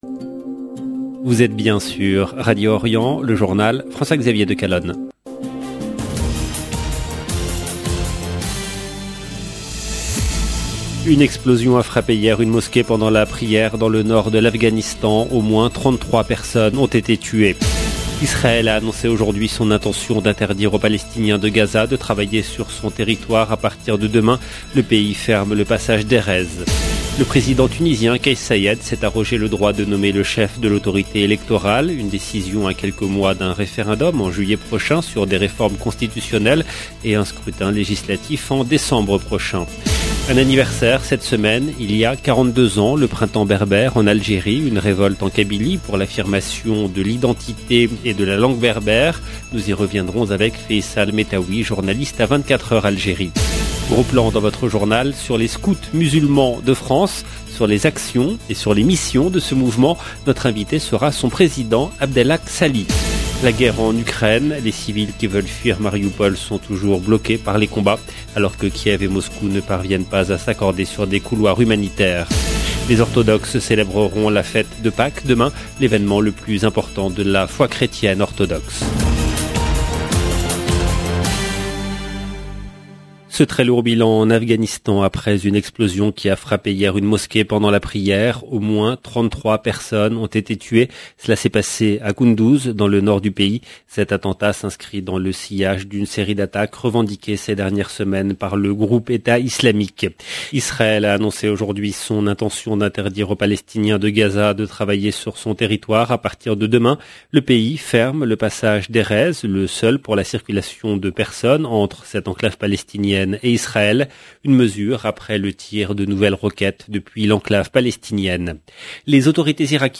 EDITION DU JOURNAL DU SOIR EN LANGUE FRANCAISE DU 23/4/2022